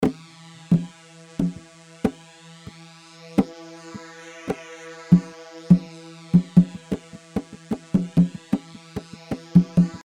Tabol F طبل